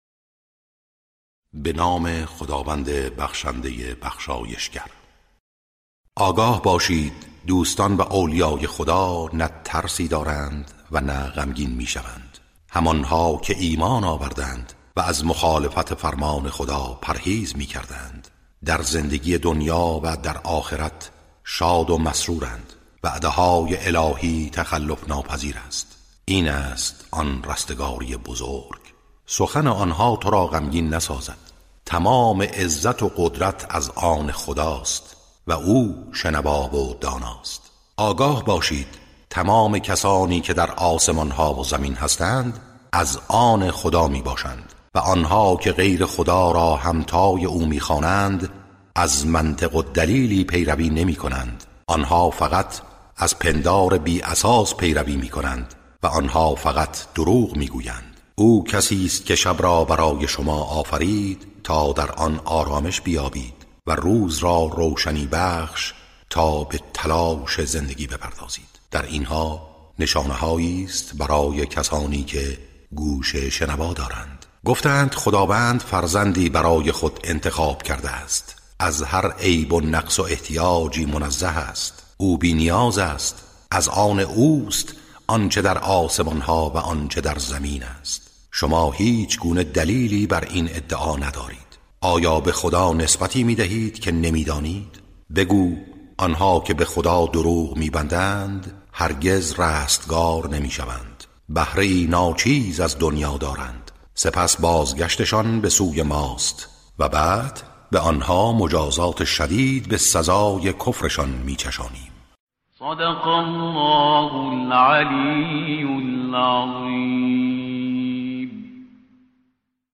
ترتیل صفحه ۲۱۶ سوره مبارکه یونس(جزء یازدهم)